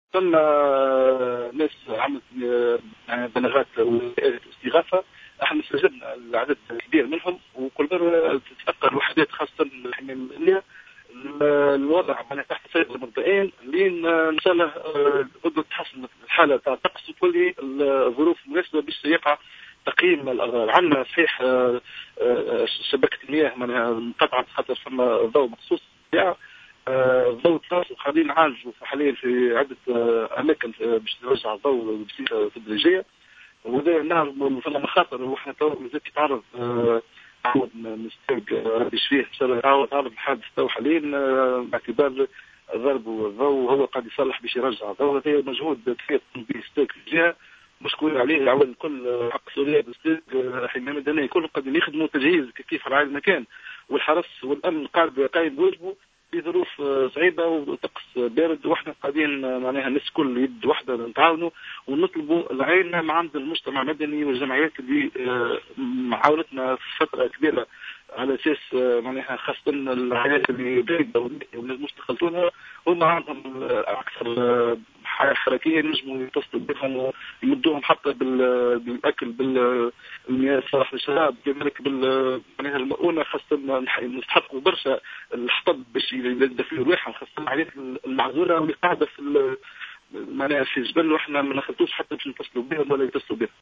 Le gouverneur de Kasserine, également président de la commission régionale de lutte contre les catastrophes, Atef Boughattas a révélé au micro de Jawhara Fm qu'un agent de la STEG a été blessé lorsqu’il tentait de réparer une passe d'électricité.